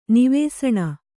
♪ nivēsaṇa